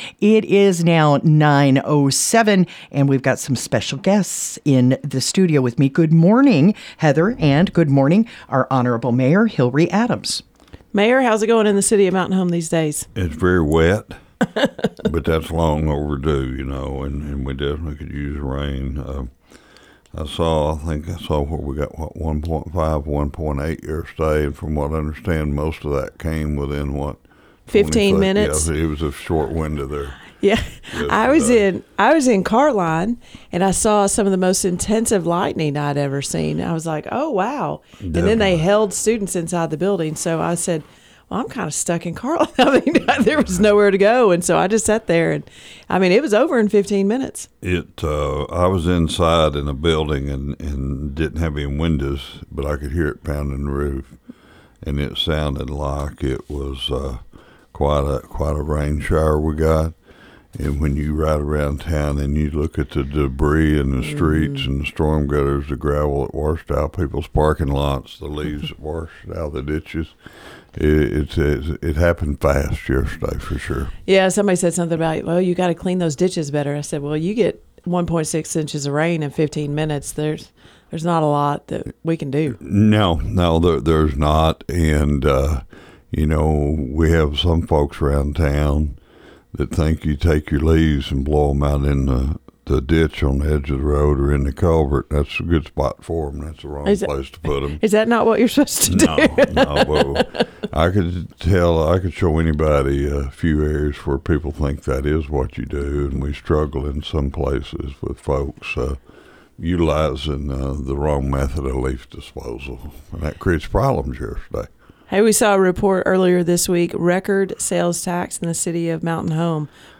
Mayor Hillrey Adams stopped by KTLO Studios to talk about the recent rain, sales tax numbers, and the new community center.